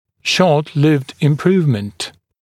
[ˌʃɔːt’lɪvd ɪm’pruːvmənt][ˌшо:т’ливд им’пру:вмэнт]краткосрочное улучшение